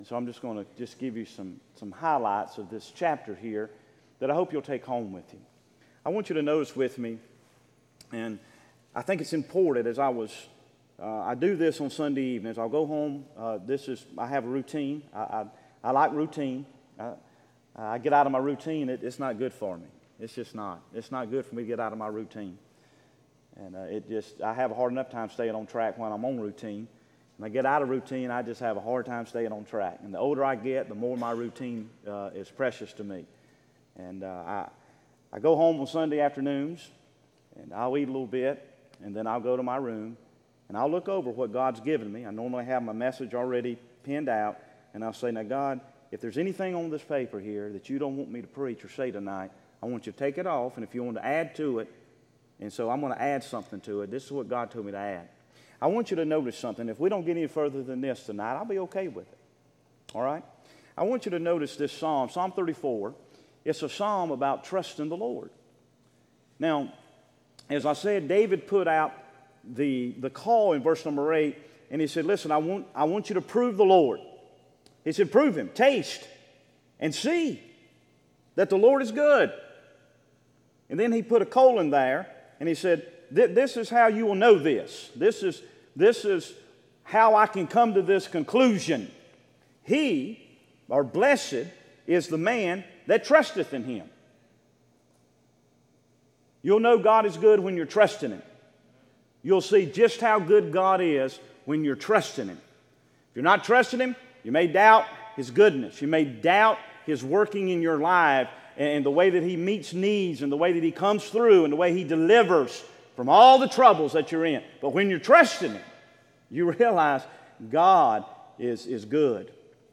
Sermons Archive • Page 60 of 166 • Fellowship Baptist Church - Madison, Virginia